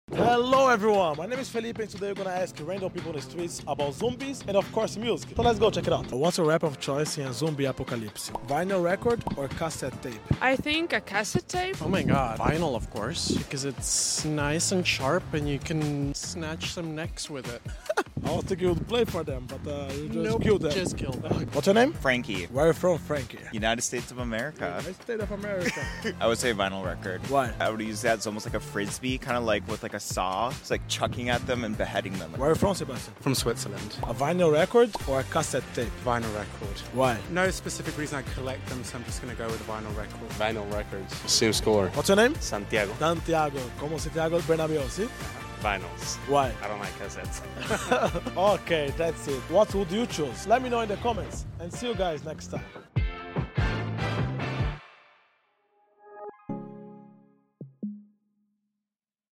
We hit the streets to find out what people think!